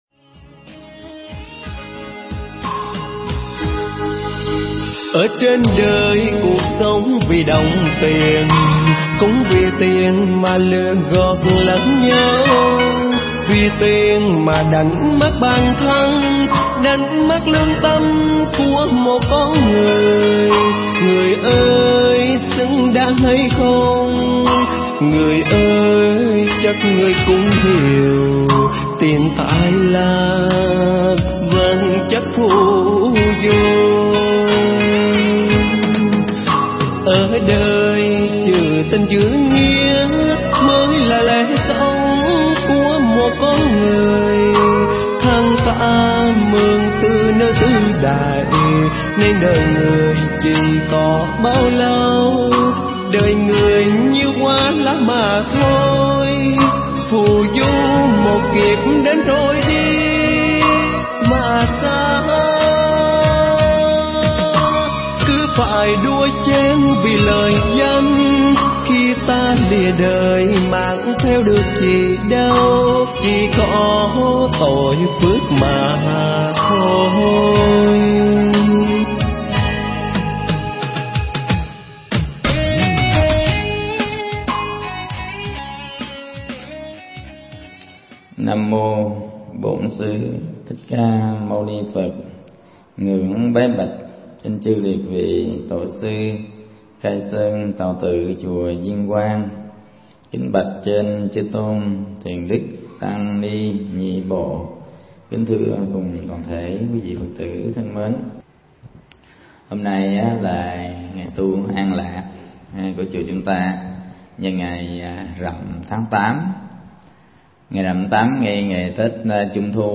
Mp3 Thuyết Giảng Phước Đời Và Phước Tu Tập
Chùa Viên Quang (Đạo tràng Niệm Phật – Khóa Tu Một Ngày)